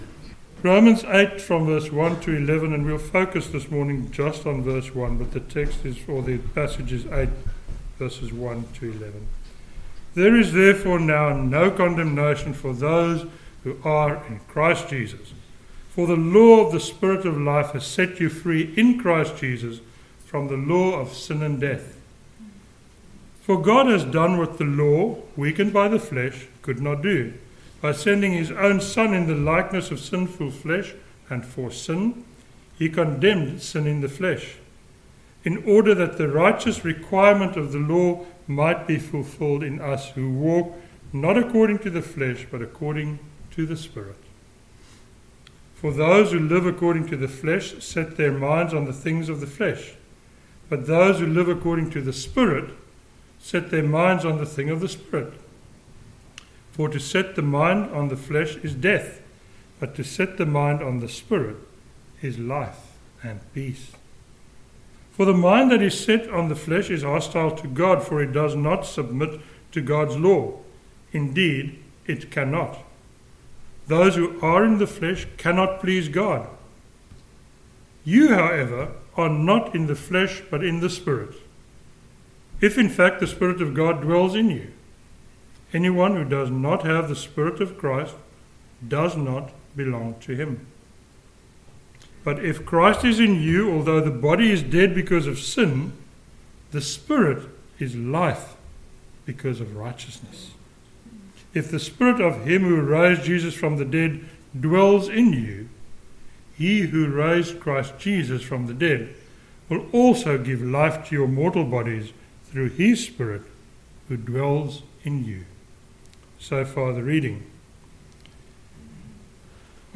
a sermon on Romans 8:1